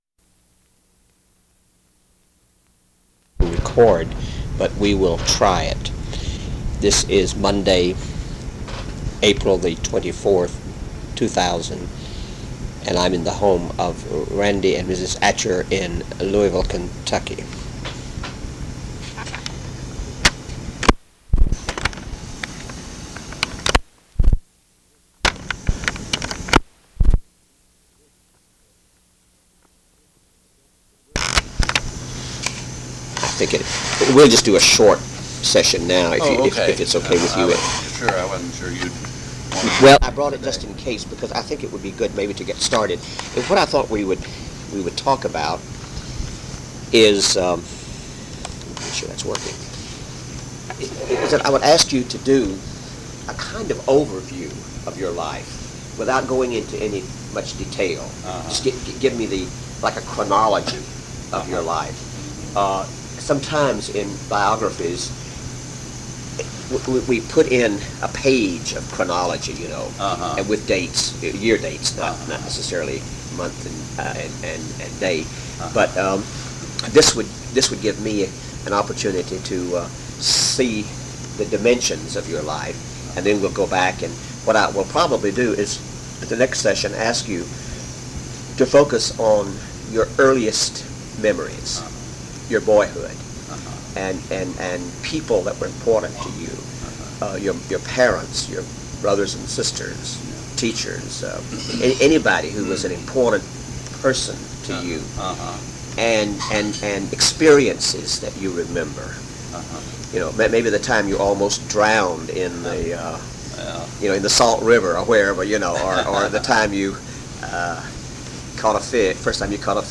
Oral History Interview with Randy Atcher